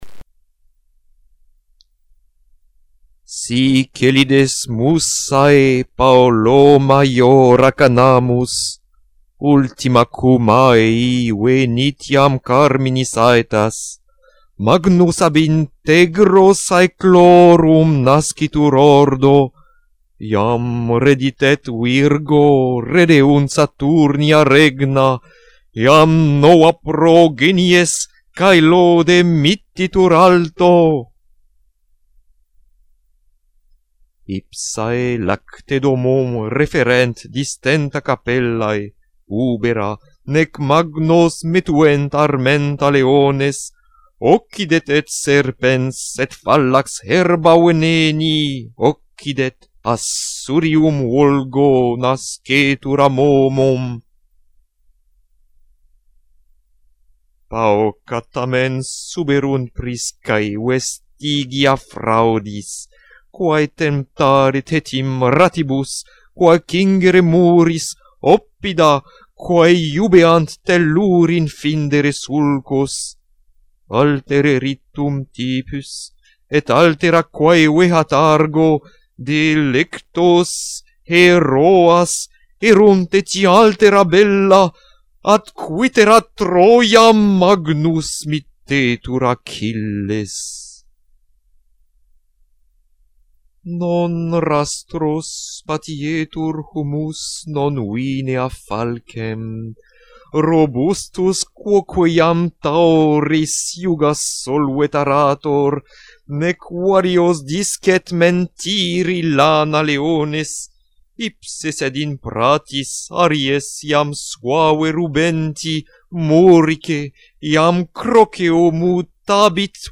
La première étape demande juste une lecture rythmique des hexamètres, sans l'intonation des mots, sans les pauses.